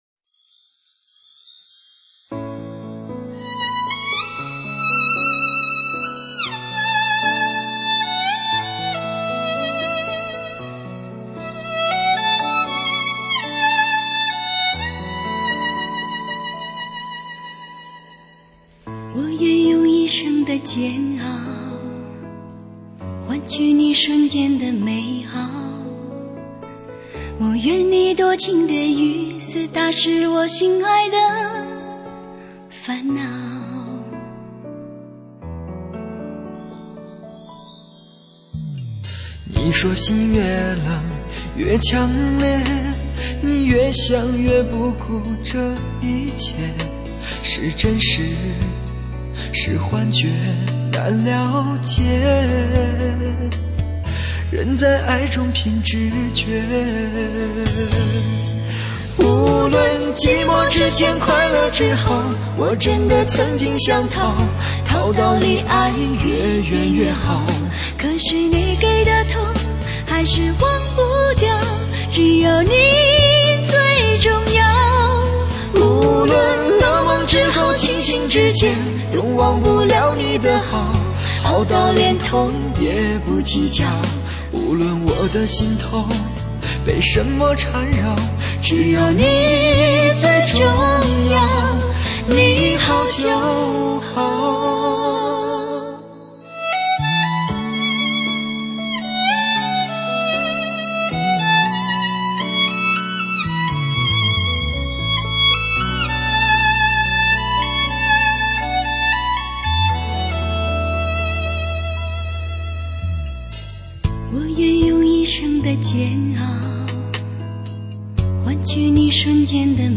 发烧精品-发烧音乐系列
蹦跳而出的声音颗粒在车厢里来回震动，冲进耳膜，滑进心肺，引起共鸣，给你营造了一个充满驾驶感觉的空间。